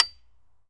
命中 金属 " 命中 金属 草坪椅01
描述：用木杆击打金属草坪椅的顶部。 用Tascam DR40录制。
Tag: 草坪椅 音调 音色 罢工 音调 打击乐 禁令克 碰撞 笔记 金属 金属 打击乐 草坪 冲击